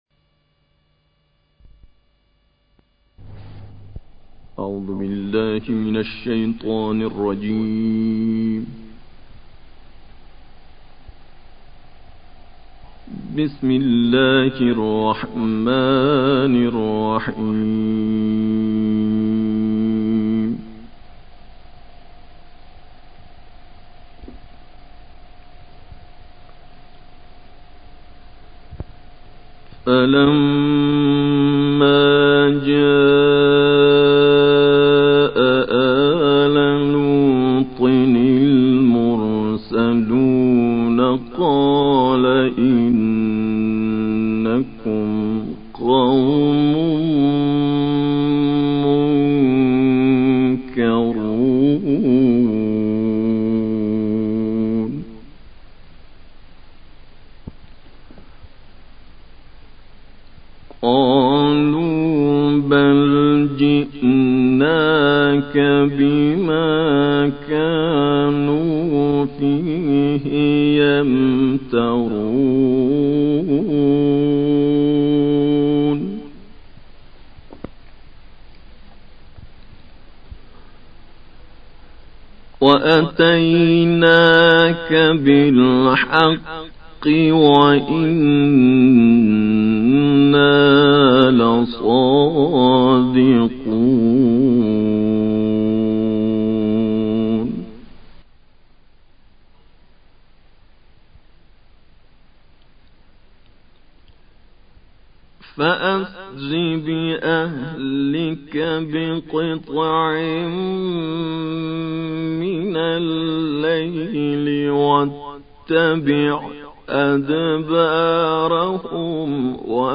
تلاوت استودیویی برای علاقمندان خیلی مفید است